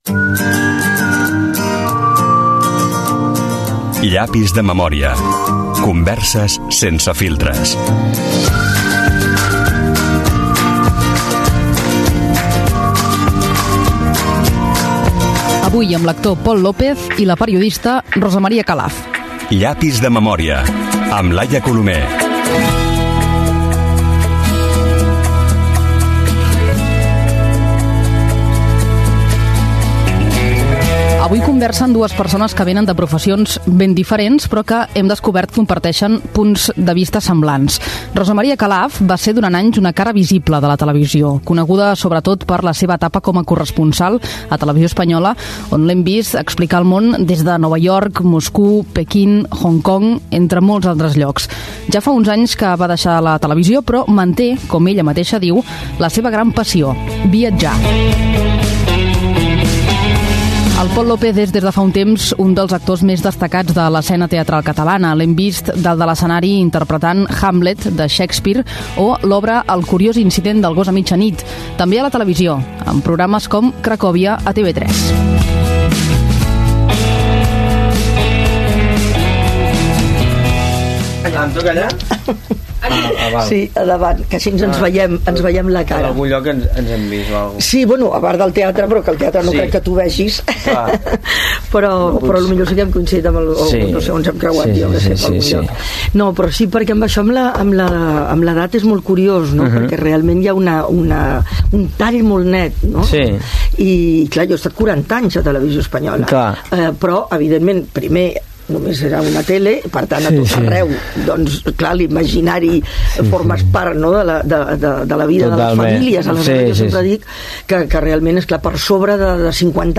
Careta del programa presentació de la periodista Rosa Calaf i de l'actor Pol López. Conversa entre els dos invitats al programa
Entreteniment